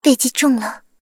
追猎者小破语音1.OGG